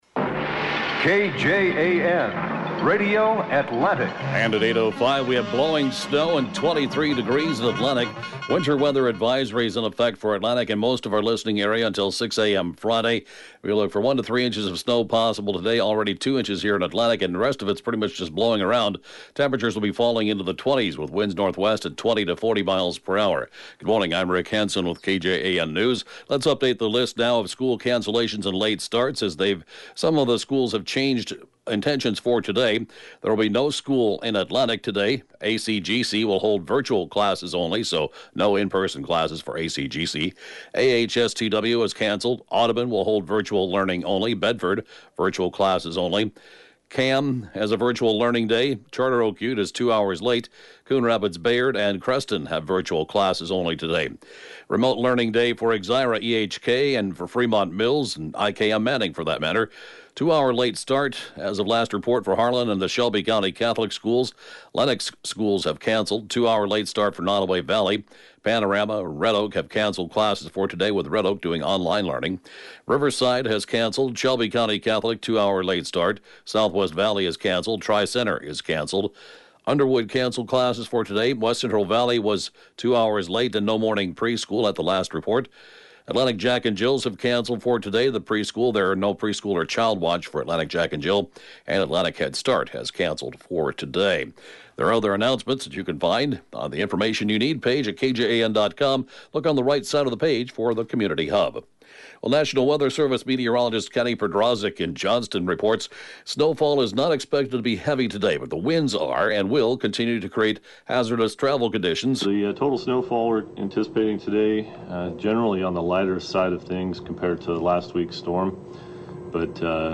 News, Podcasts